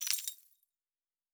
Objects Small 01.wav